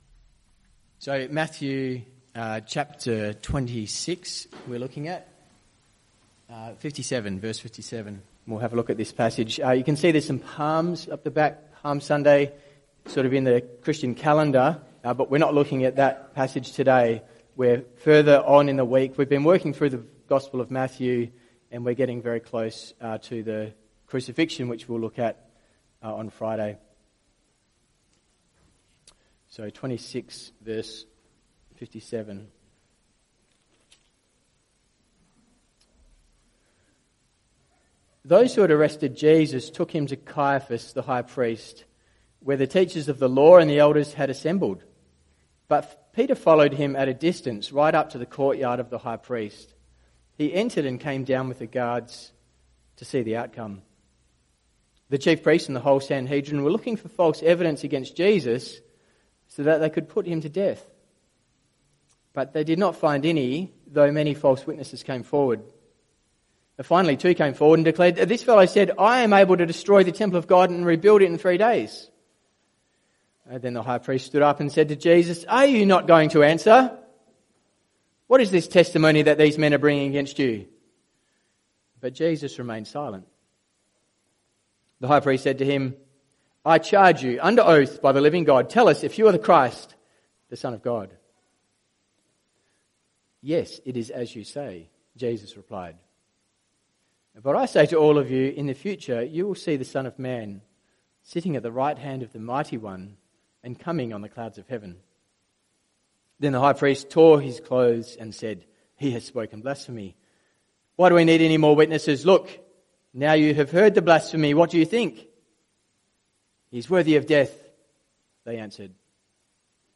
CBC Service: 29 Mar 2026 Series
Type: Sermons